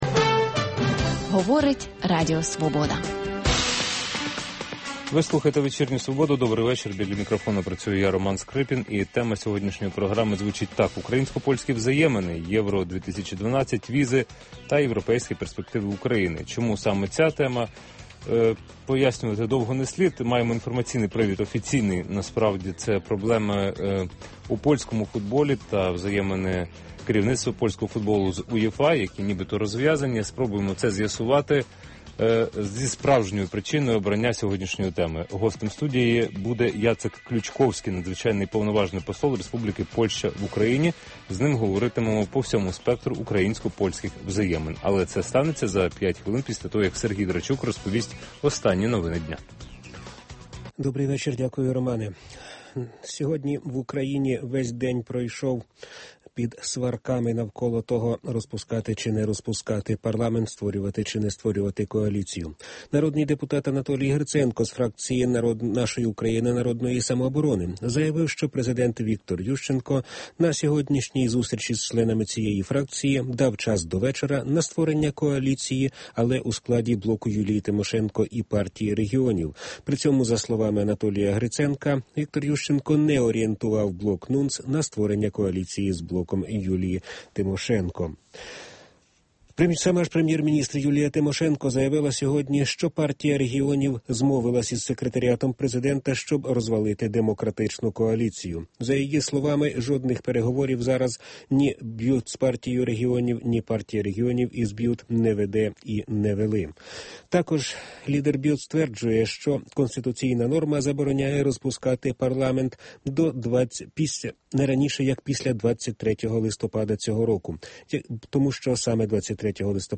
Інтелектуальна дуель у прямому ефірі. Дискусія про головну подію дня, що добігає кінця.